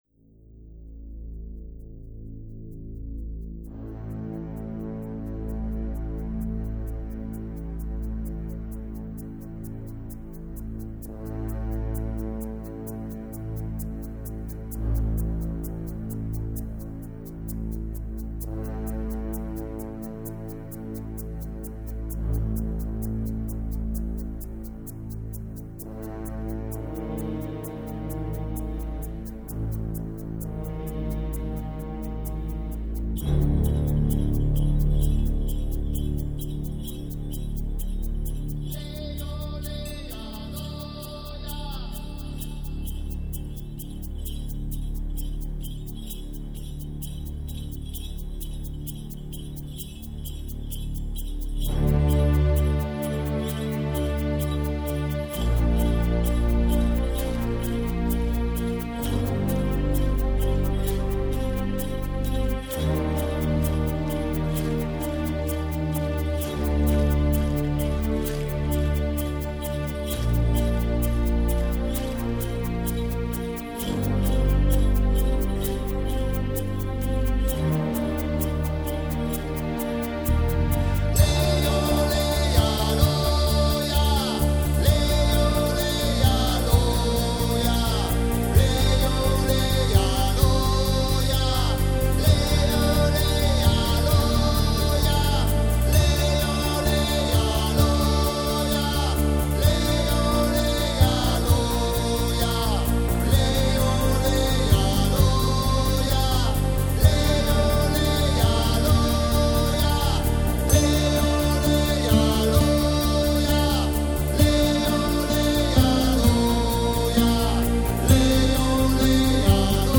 soprano and tenor saxophones
bass, synth programs, percussion
guitar, voices